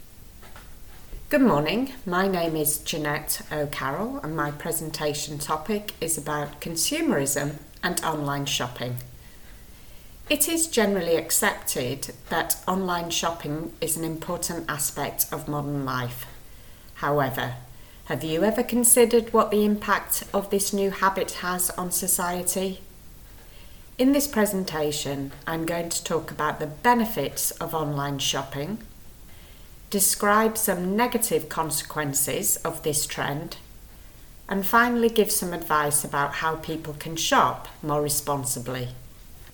• Exam-ready recorded monologue (MP3)
b2-monologue-consumerism-online-shopping-sneak-peek.mp3